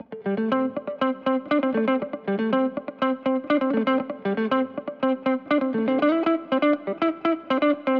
31 Guitar PT1.wav